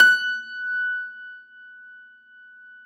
53f-pno20-F4.aif